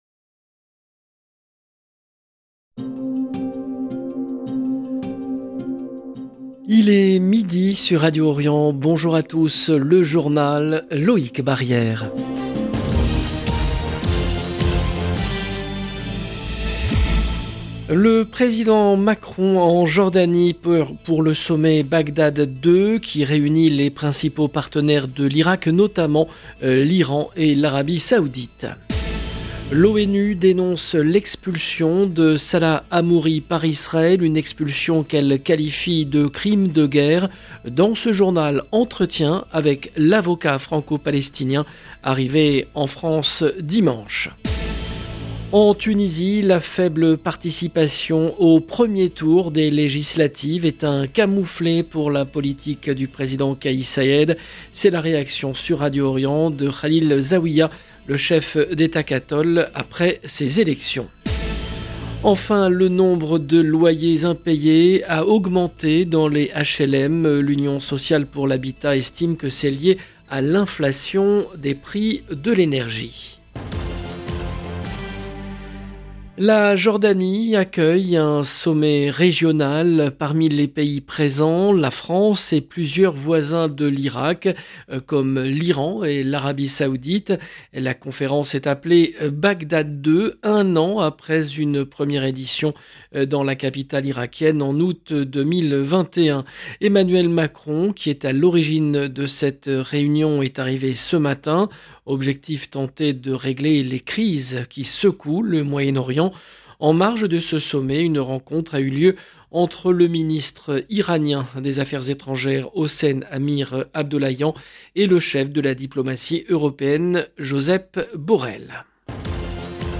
Dans ce journal, entretien avec l’avocat franco-palestinien arrivé en France dimanche.